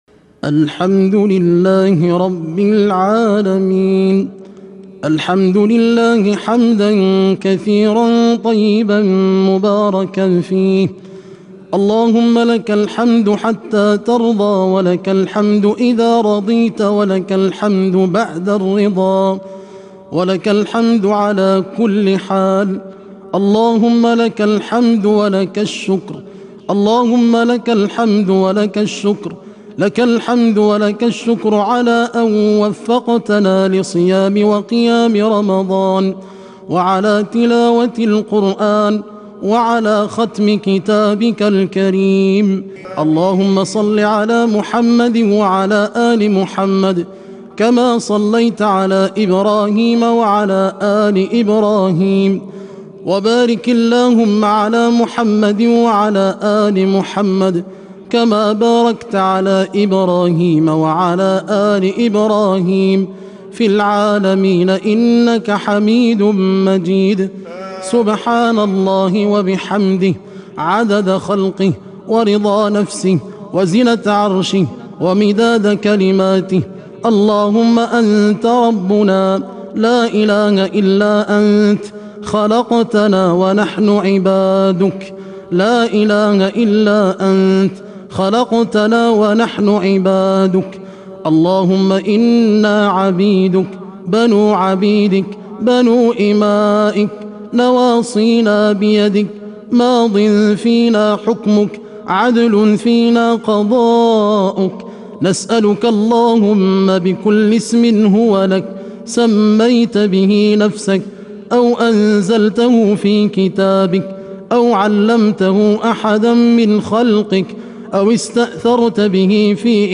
دعاء ختم القرآن الكريم 1437هـ
تسجيل لدعاء ختم القرآن الكريم